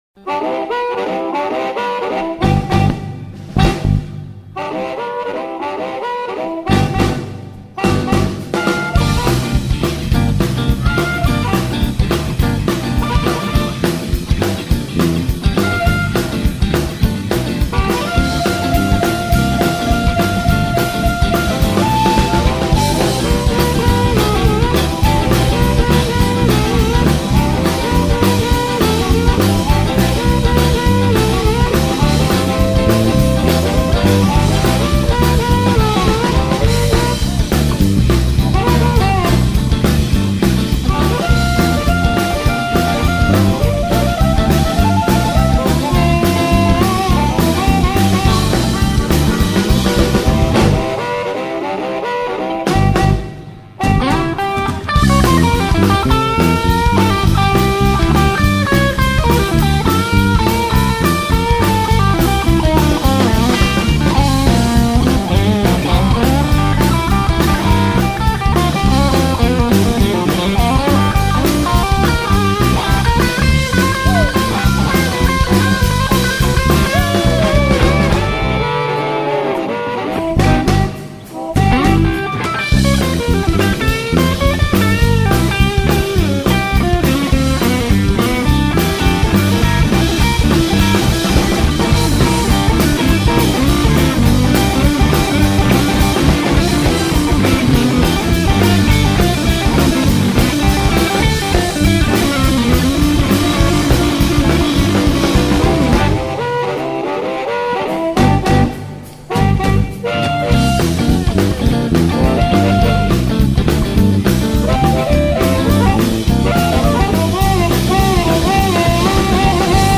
Un fragment de música amb harmònica en format MP3